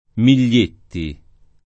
[ mil’l’ % tti ]